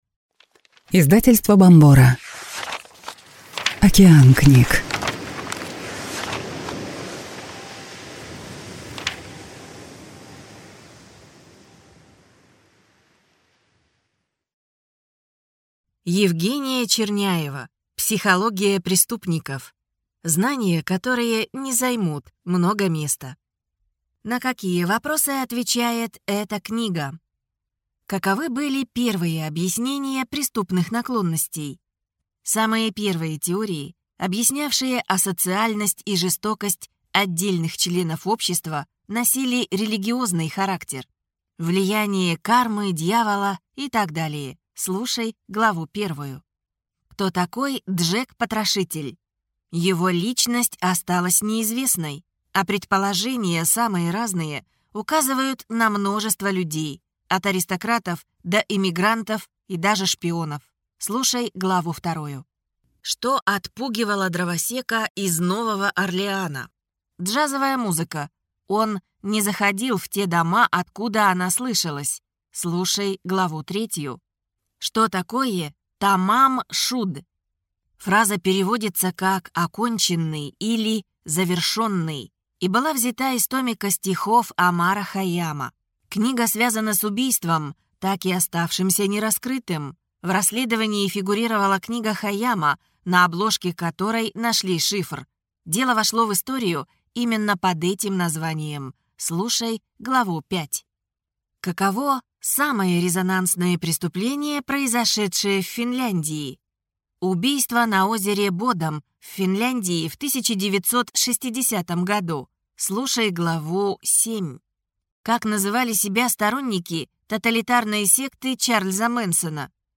Аудиокнига Психология преступников. Знания, которые не займут много места | Библиотека аудиокниг